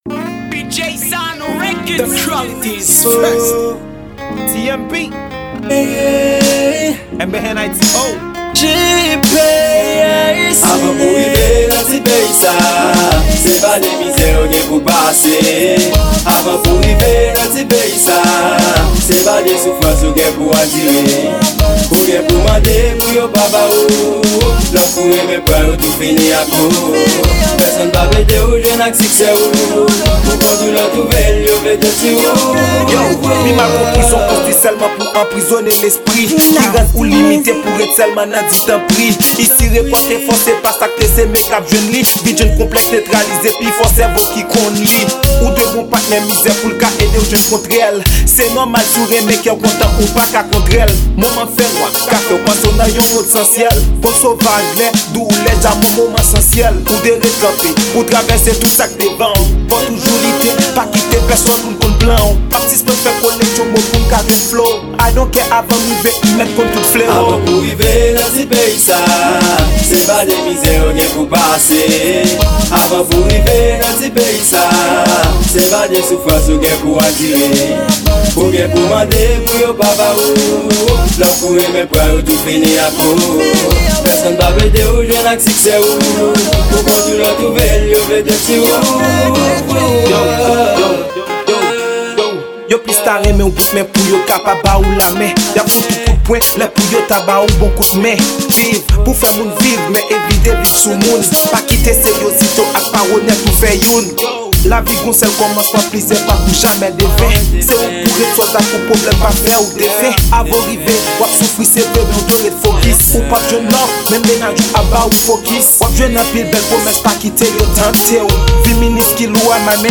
Genre: Rap-Social.